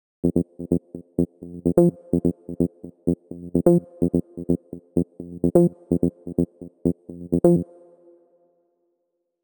VDE 127BPM Rebound Seq 1 Root F SC.wav